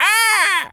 crow_raven_squawk_05.wav